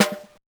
drum.ogg